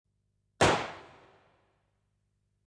Descarga de Sonidos mp3 Gratis: bala 2.
descargar sonido mp3 bala 2